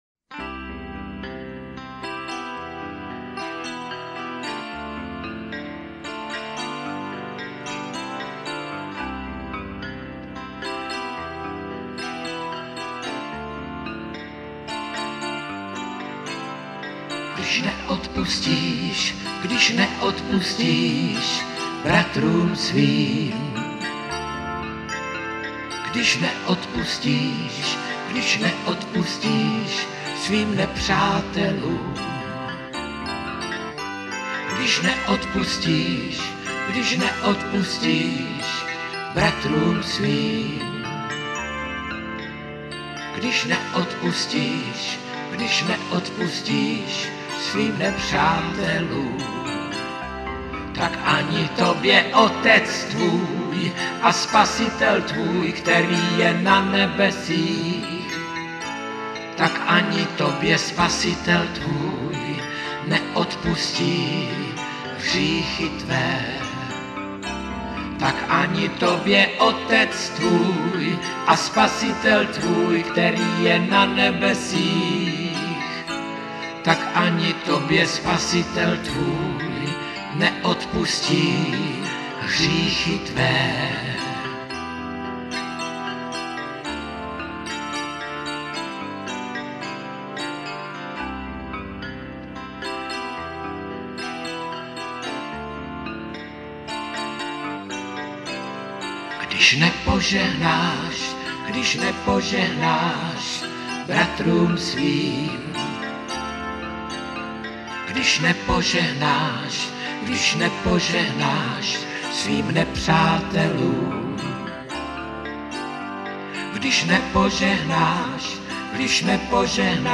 Písně ke chvále a uctívání